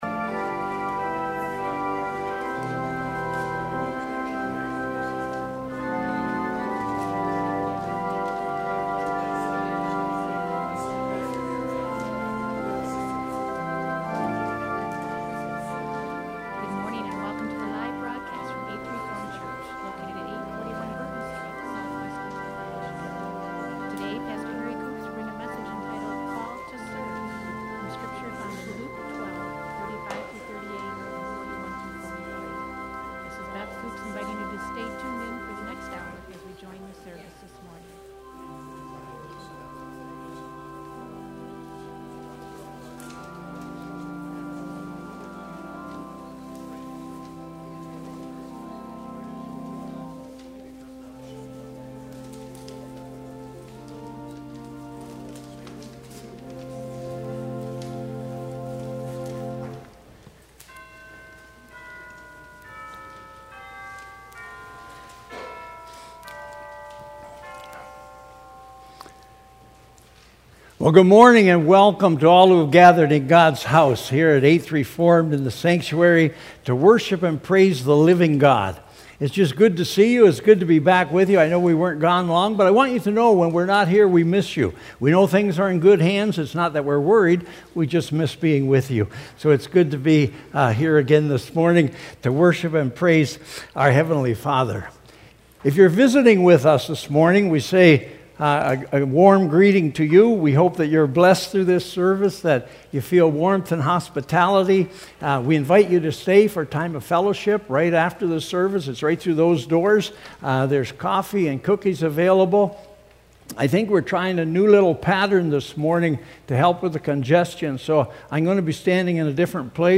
Worship Services | Eighth Reformed Church